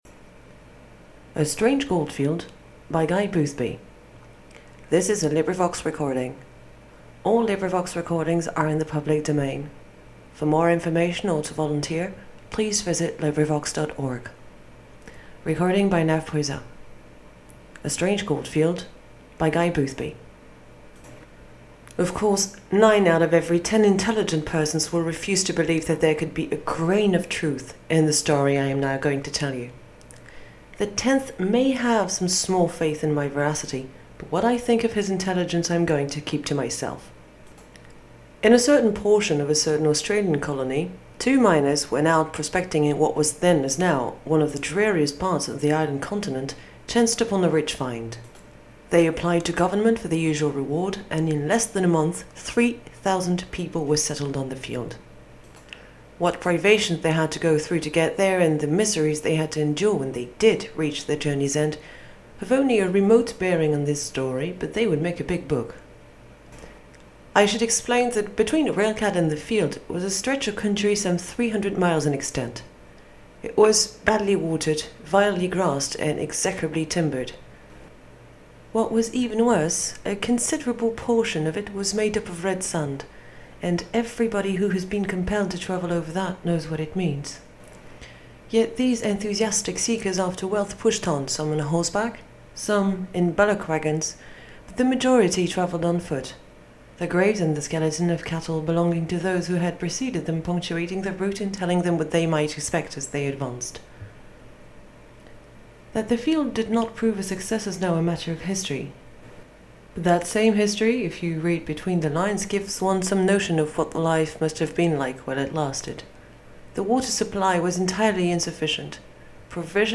Audio from Librivox